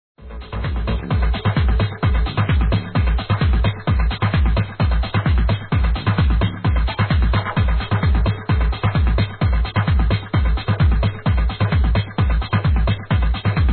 Un son très profond, très TechHouse